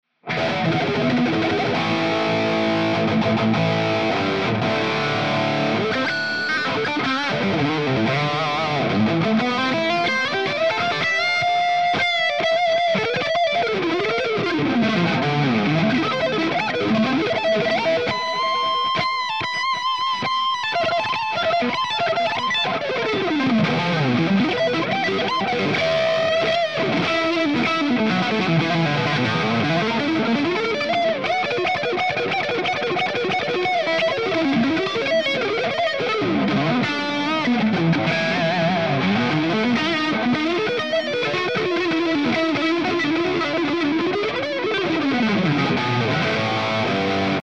Improv
RAW AUDIO CLIPS ONLY, NO POST-PROCESSING EFFECTS
Hi-Gain